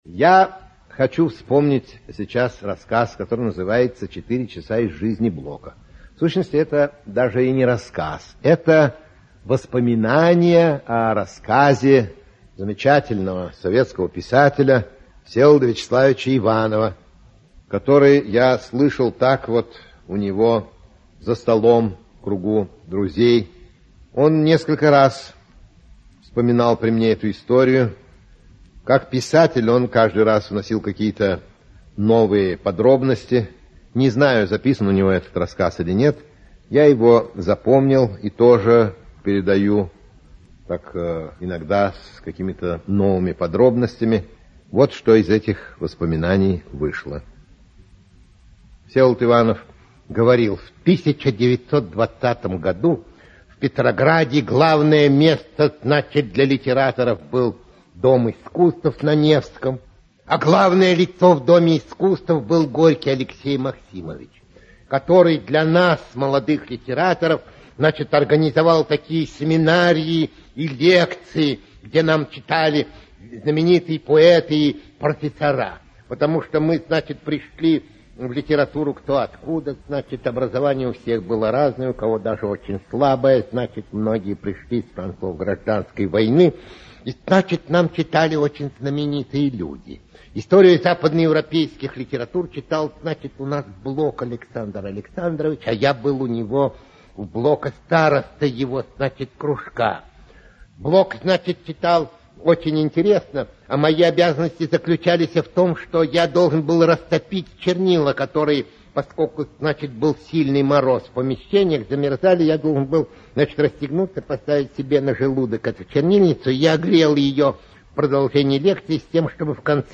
Аудиокнига 4 часа из жизни Блока, Римская опера | Библиотека аудиокниг
Aудиокнига 4 часа из жизни Блока, Римская опера Автор Ираклий Андроников Читает аудиокнигу Ираклий Андроников.